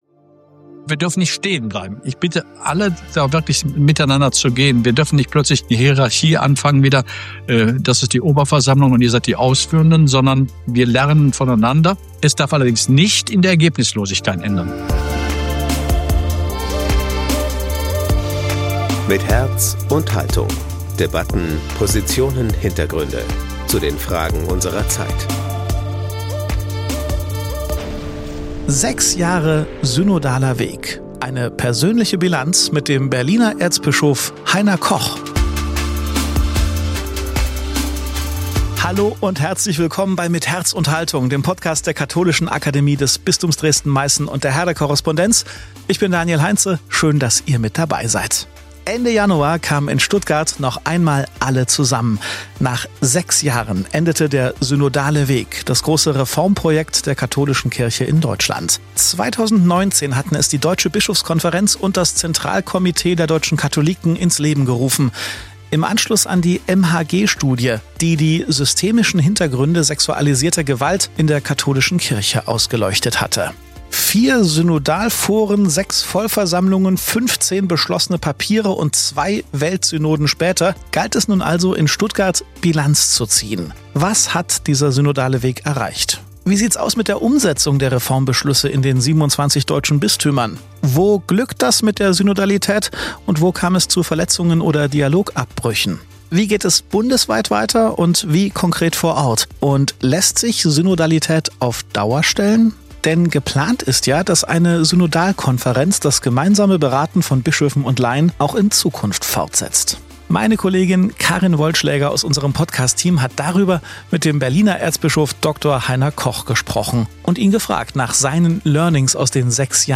Beschreibung vor 2 Monaten Nach sechs Jahren endet der Synodale Weg, das große Reformprojekt der katholischen Kirche in Deutschland. Am Rande der letzten Synodalversammlung in Stuttgart haben wir mit Erzbischof Dr. Heiner Koch gesprochen: Was hat der Synodale Weg erreicht?